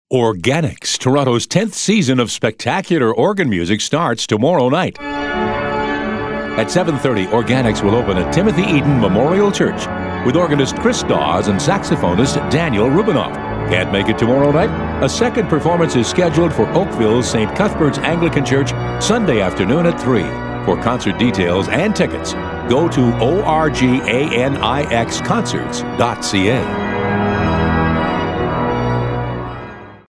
Audio: Classical 96 Radio ad for 2015 Organix performances https